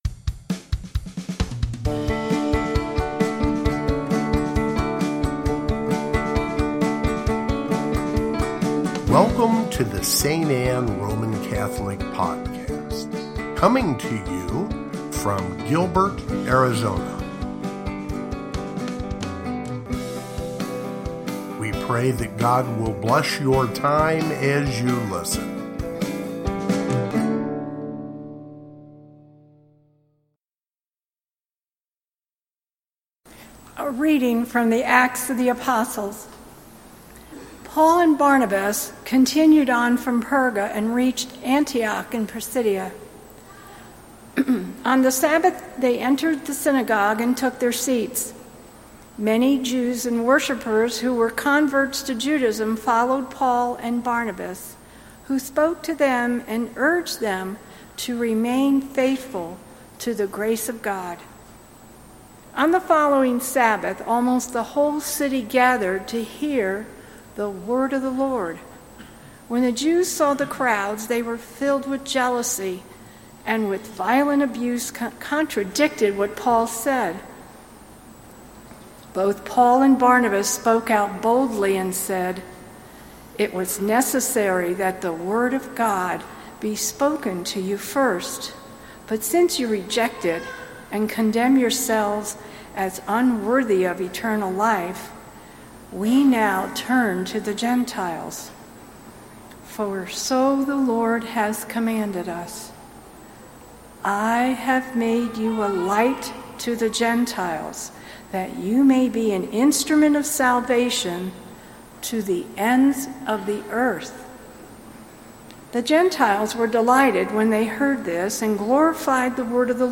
Gospel, Readings, Easter